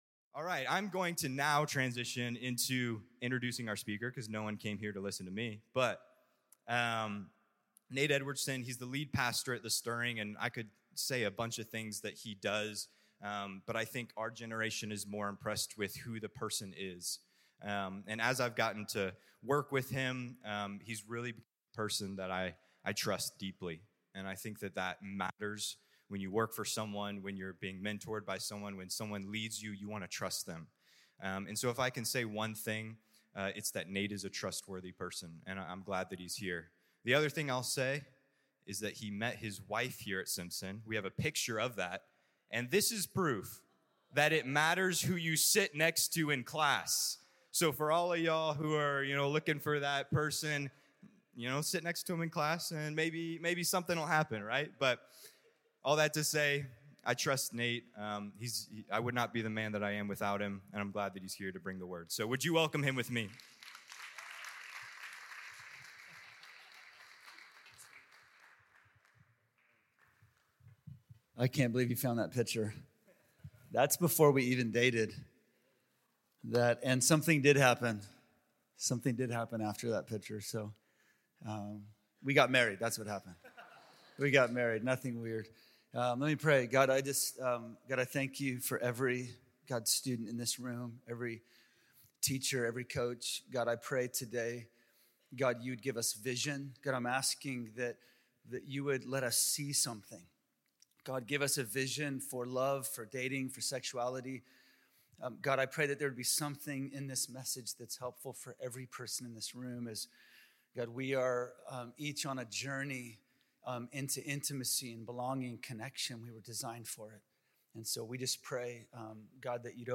This talk was given in chapel on Friday, February 14th, 2025 God Bless you.